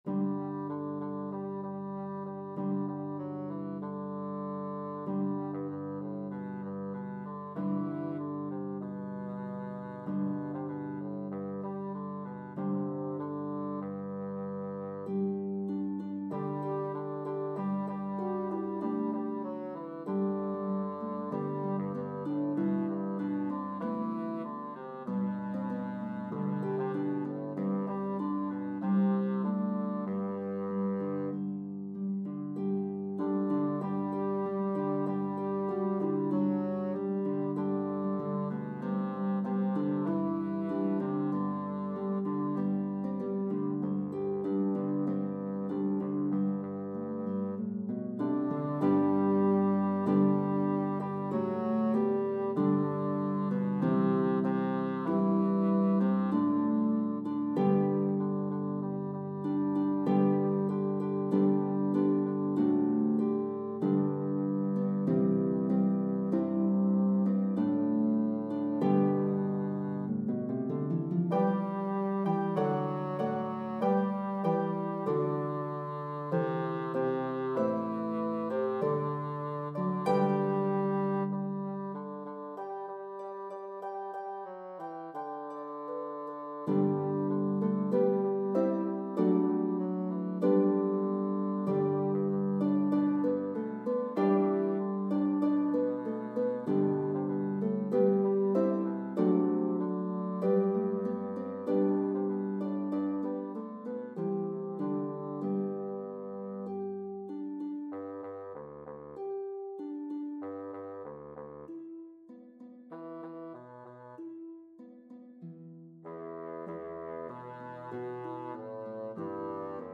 a medley of traditional English & Irish Carols